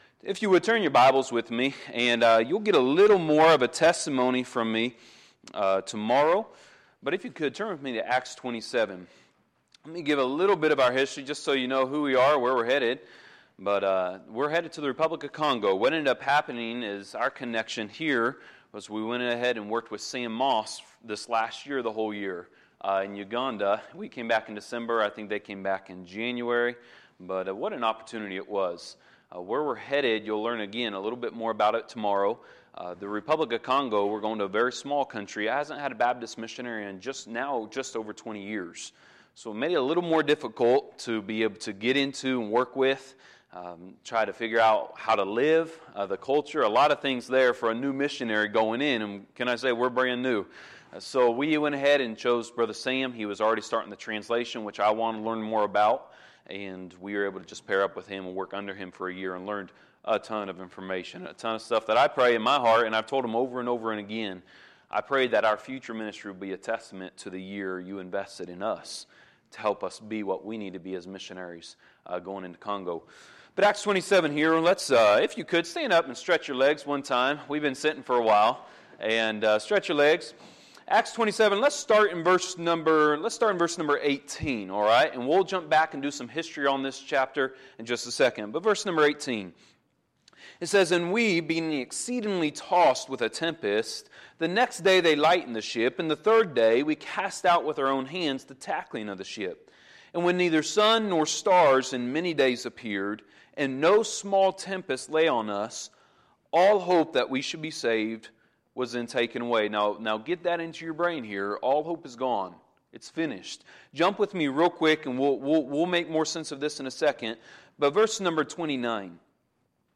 Sunday, September 24, 2017 – Missions Conference Sunday PM Service